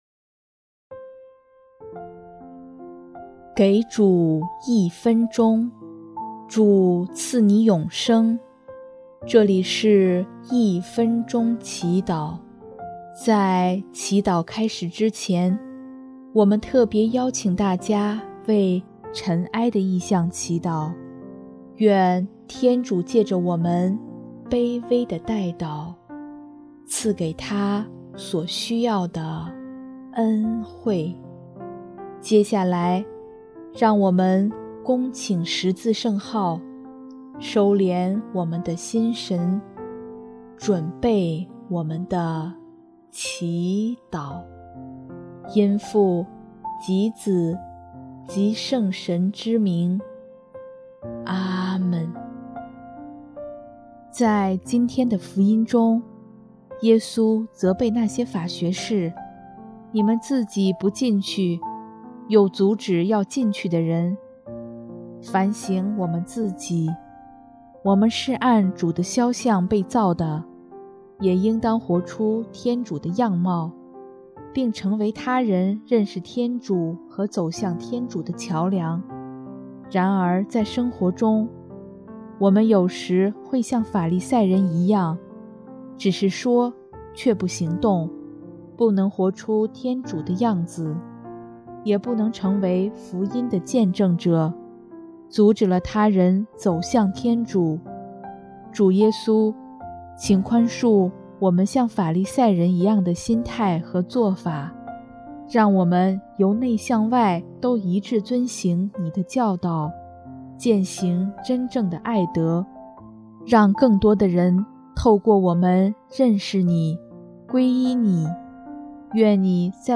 【一分钟祈祷】|10月16日 成为他人走向天主的桥梁